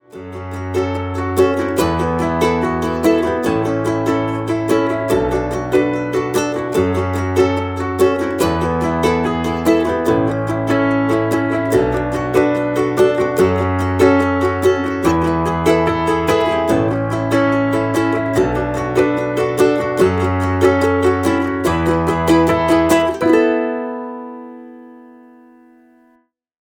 pianino + ukulele